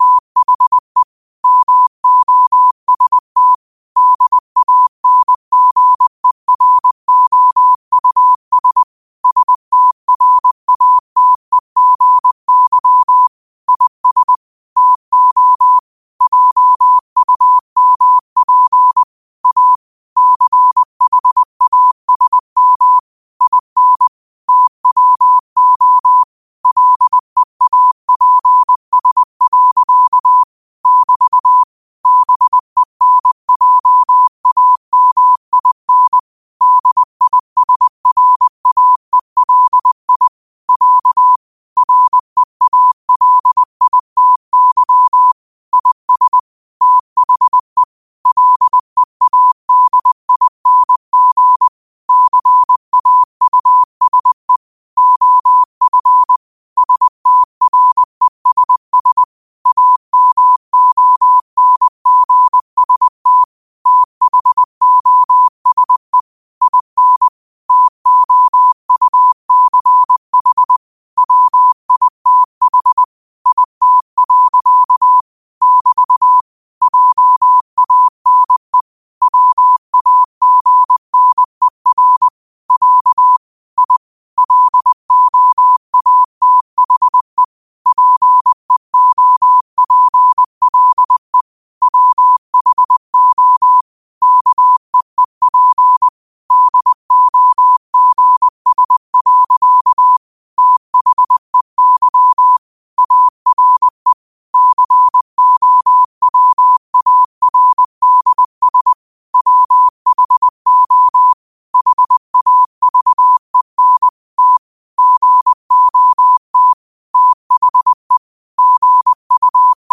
New quotes every day in morse code at 20 Words per minute.